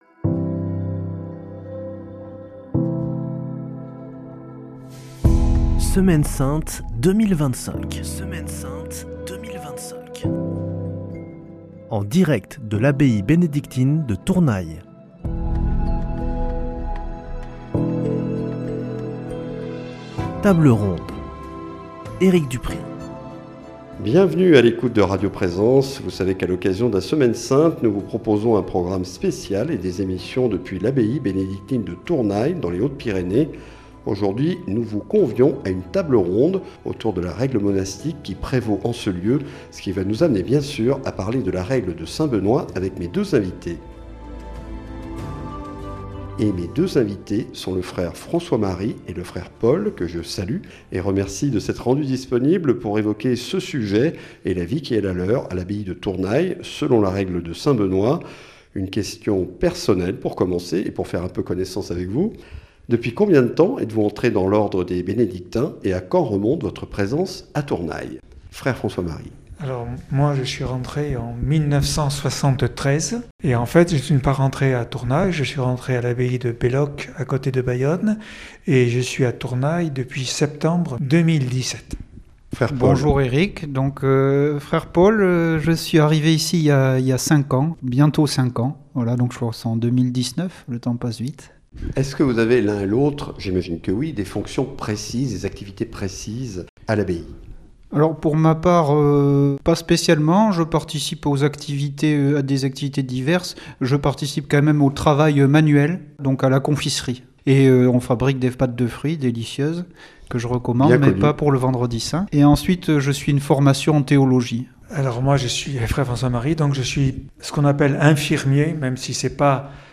Table ronde - Règle monastique